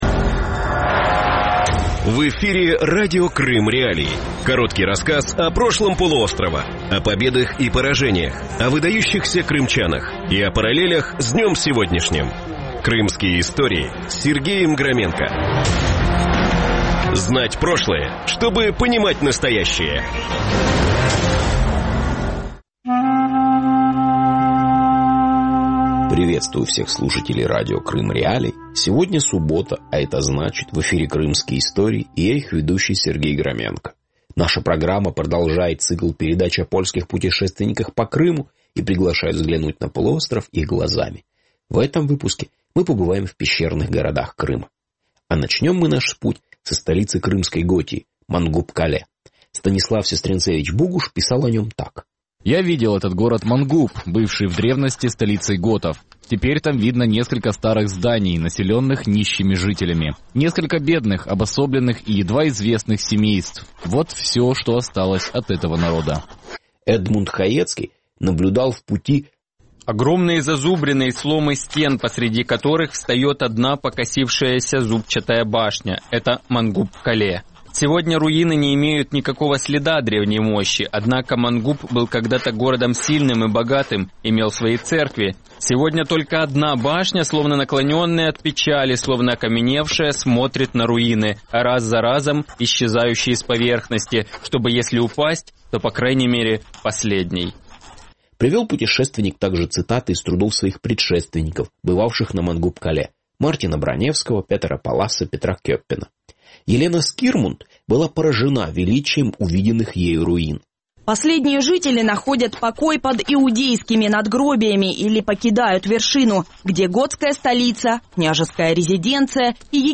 Програма звучить в ефірі Радіо Крим.Реалії